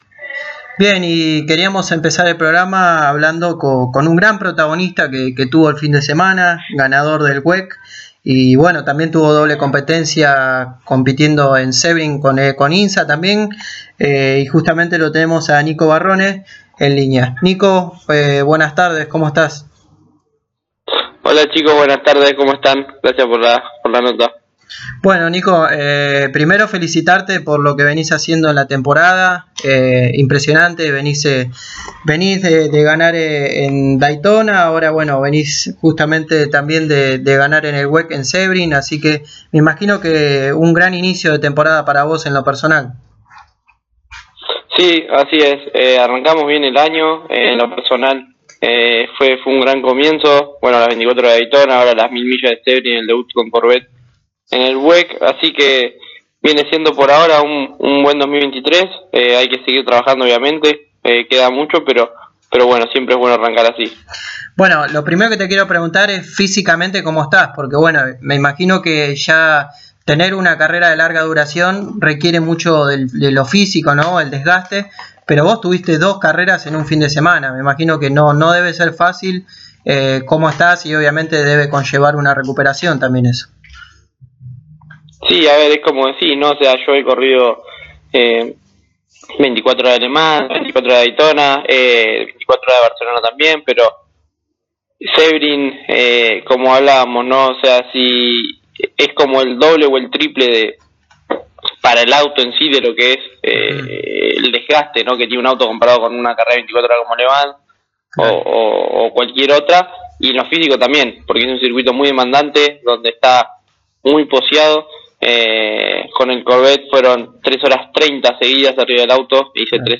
El piloto de Ingeniero Maschwitz pasó por los micrófonos de Pole Position y habló de la doble actividad que tuvo el fin de semana en Sebring, donde logró el triunfo en la categoría GTE-AM con el equipo Corvette Racing en el WEC y logró el cuarto lugar en las 12 hs de Sebring con el equipo AWA Racing.